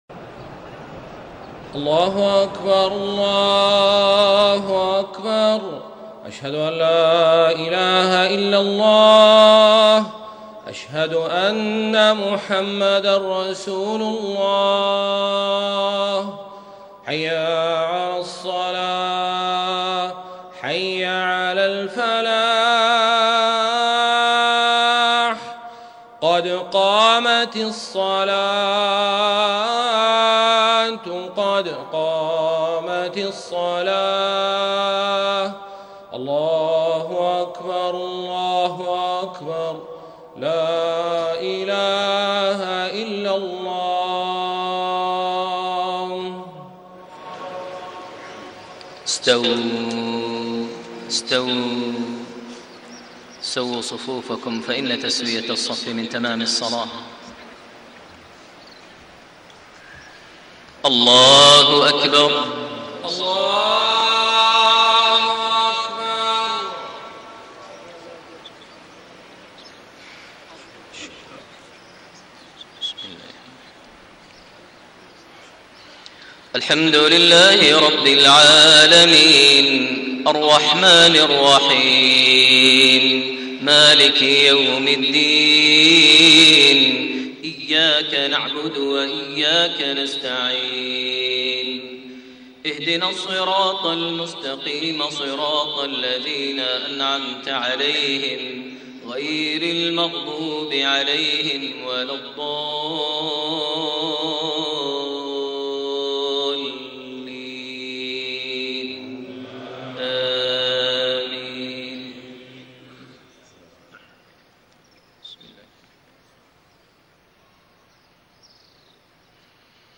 صلاة المغرب6-4-1432 سورة الفجر > 1432 هـ > الفروض - تلاوات ماهر المعيقلي